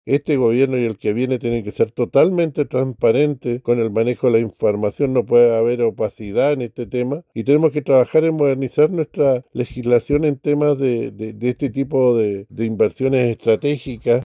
A su vez, el senador independiente y presidente de la Comisión de Transportes, Alejandro Kusanovic, planteó que la transparencia debe ser el eje del debate y que es necesario avanzar en regulaciones para este tipo de inversiones.